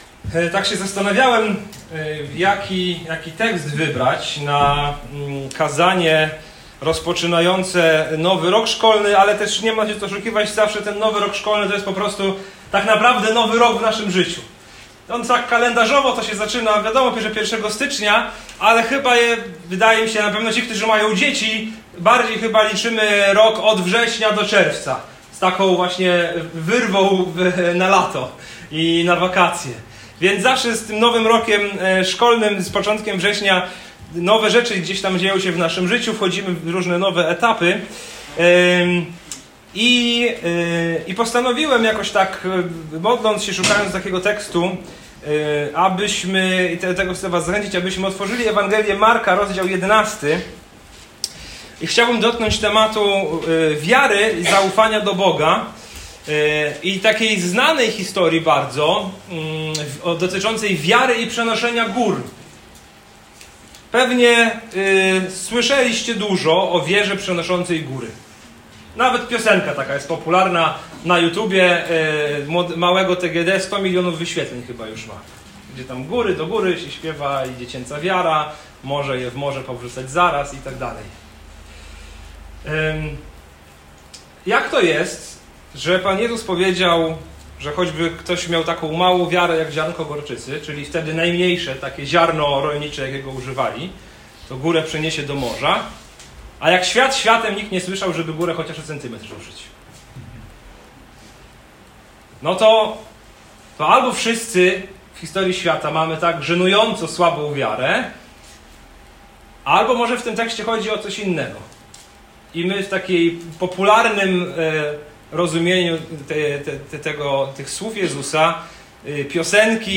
Posłuchaj tego kazania, zbadaj kontekst i zrozum jakiej wiary pragnie Bóg!